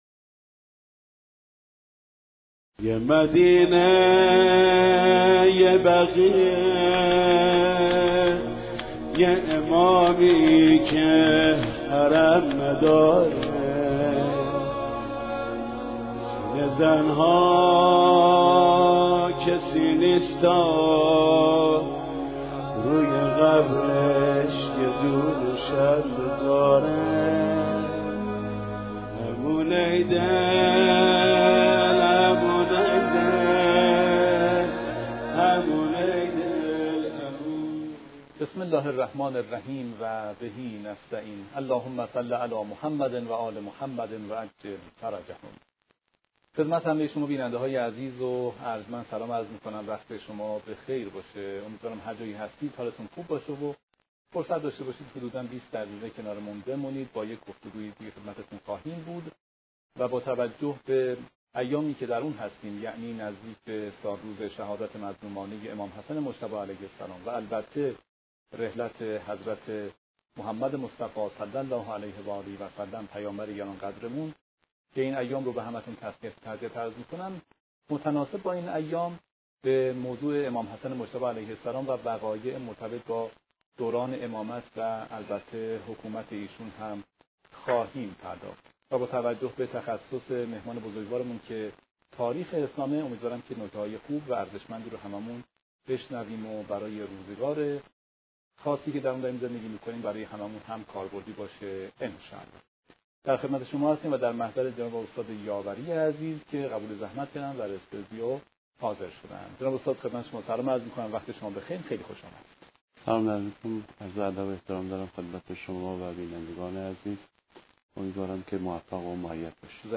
بسم الله الرحمن الرحیم مقدمه و تسلیت ایام: مجری برنامه با تسلیت ایام شهادت امام حسن مجتبی(ع) و رحلت پیامبر اکرم(ص)، موضوع برنامه را بررسی وقایع دوران امامت و حکومت امام حسن(ع) به ویژه صلح ایشان با معاویه معرفی می‌کند.